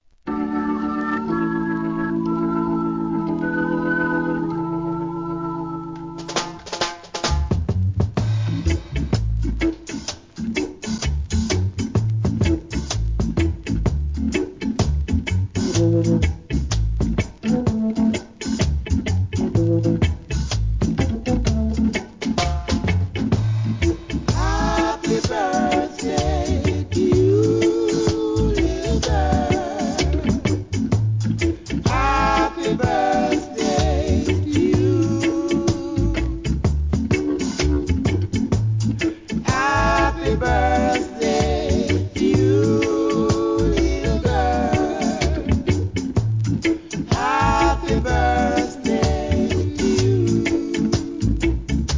REGGAE
ムーディーなLOVERS REGGAEを集めた好コンピ第9弾!!